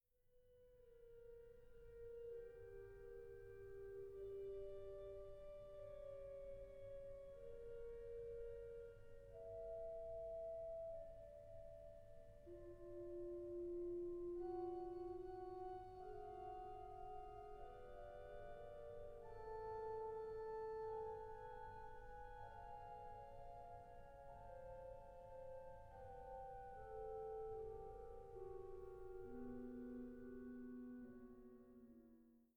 an der Orgel der Jesuitenkirche St. Michael zu München